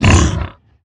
sounds / mob / hoglin / hurt3.ogg
hurt3.ogg